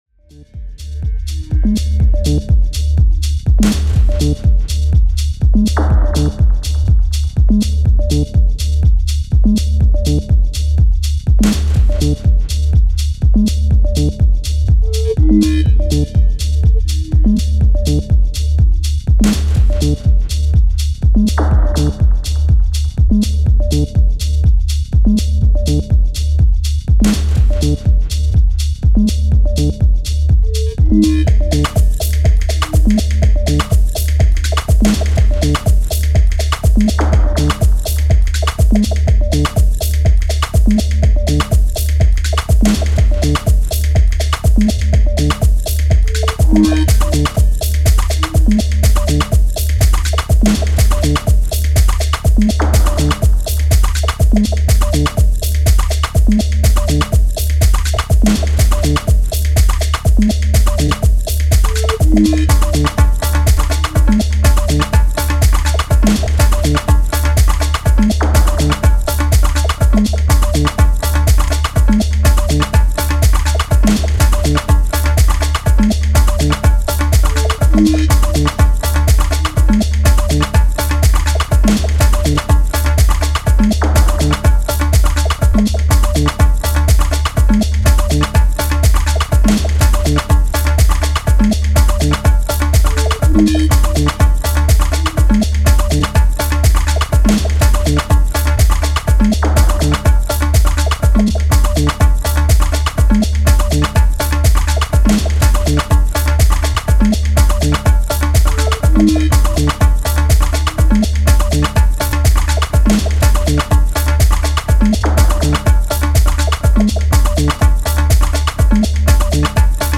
モダンでミニマルなハウス・グルーヴをストイックに追い求めていった、職人的な気質が光る一枚に仕上がっています！
エレクトリックでドンシャリな音色をミニマルに構成したトライバルライクなb1！